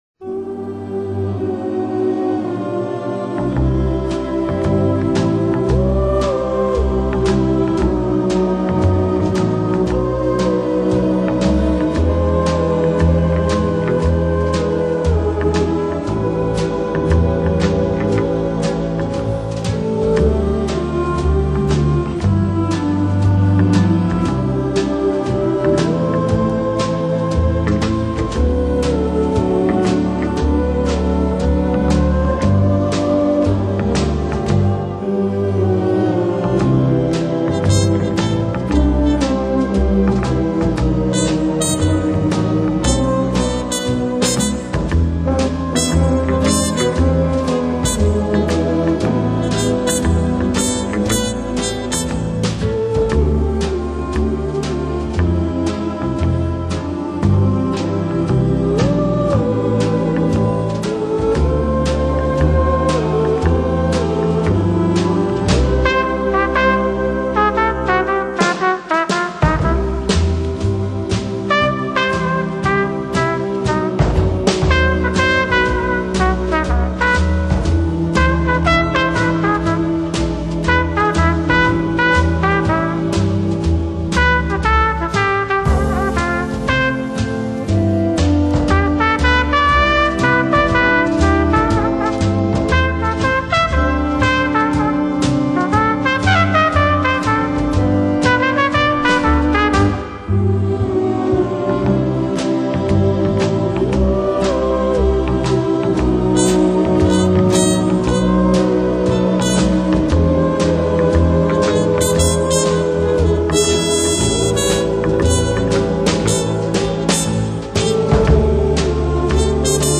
击乐的节奏表现，形成了独自的演奏风格，加上大量作品的原创性，使其当之无愧地